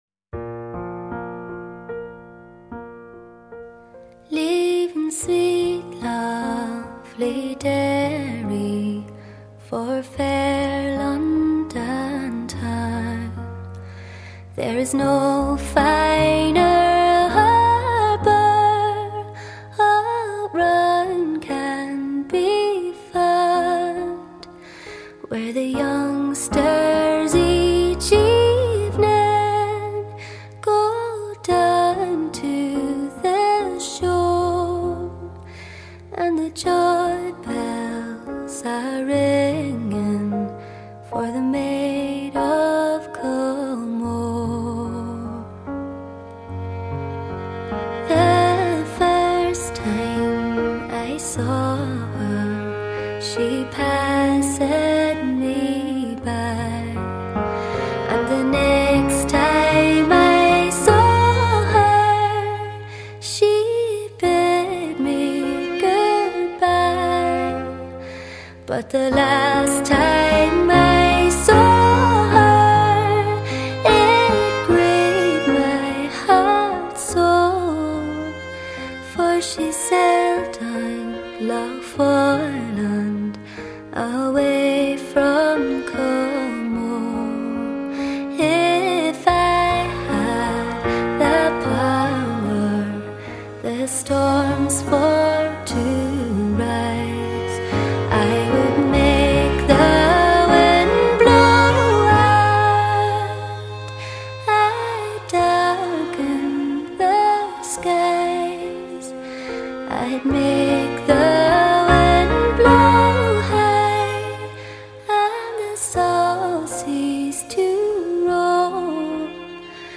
几乎所有的歌曲都是具有传统习俗风格的
很好听啊， 声音不错，唱功也好，谢谢分享哦